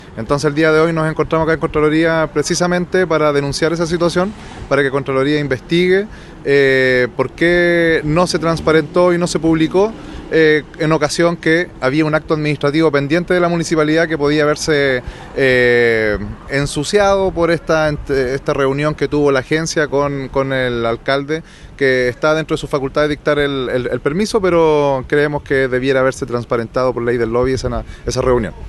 El concejal, Felipe Bustos, dijo que decidieron presentar esta denuncia para que se investigue la eventual irregularidad.